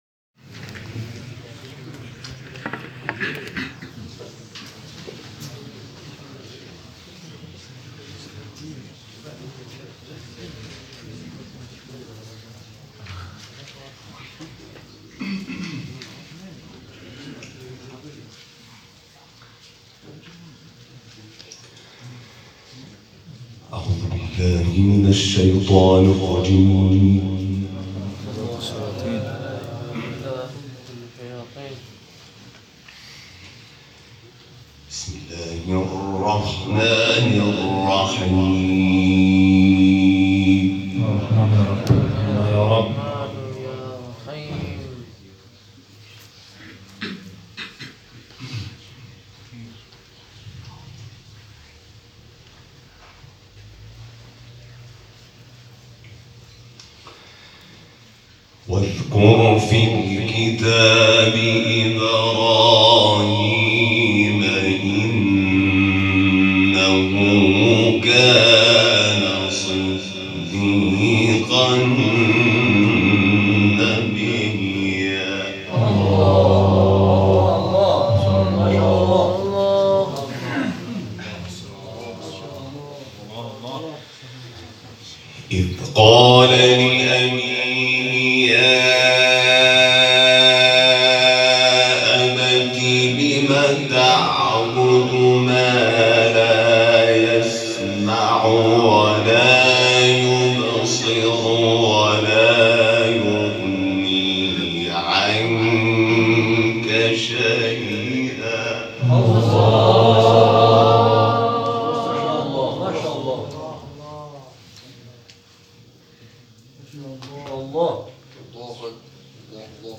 استخراج صدا - تلاوت